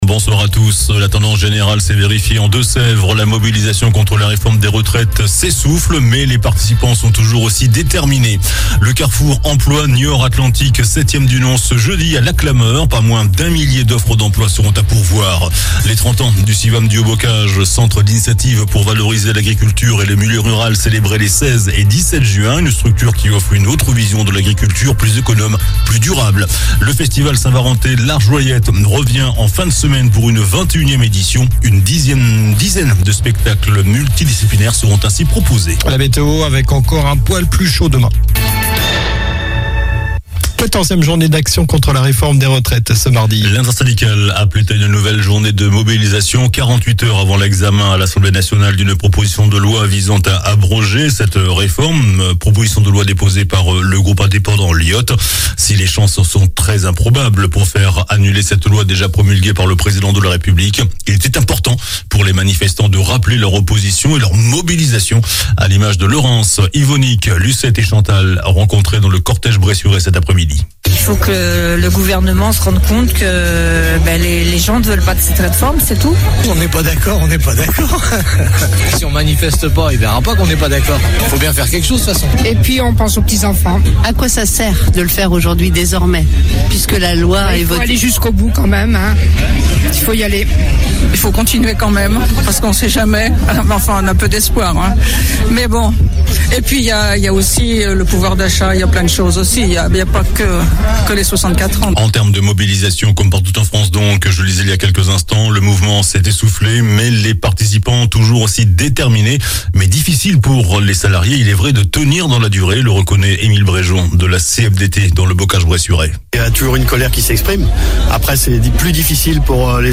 JOURNAL DU MARDI 06 JUIN ( SOIR )